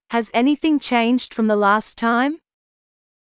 ﾊｽﾞ ｴﾆｼﾝｸﾞ ﾁｪﾝｼﾞﾄｩｯ ﾌﾛﾑ ｻﾞ ﾗｽﾄ ﾀｲﾑ